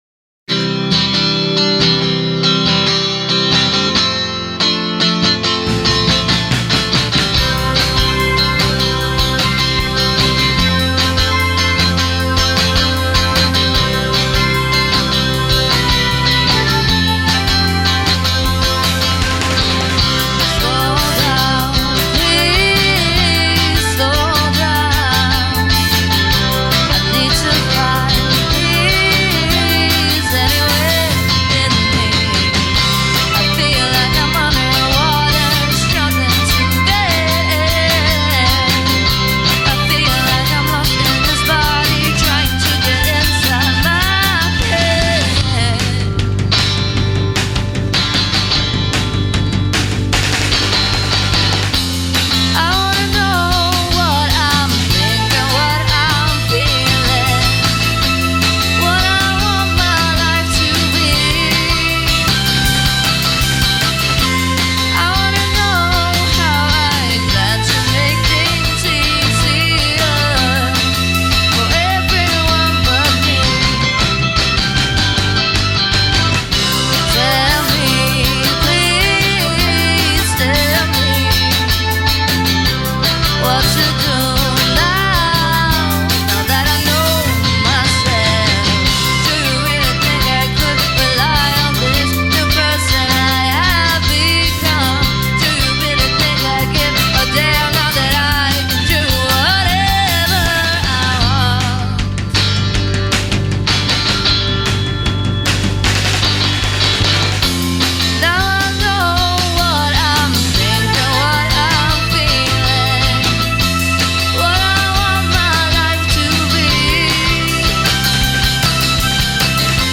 Charming, catchy and kyootness on steroids.